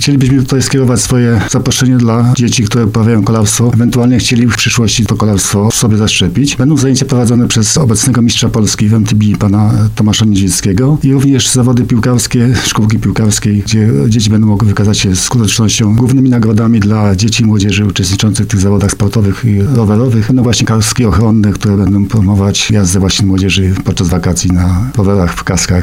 – mówił Piotr Rybski, sołtys Zbylitowskiej Góry.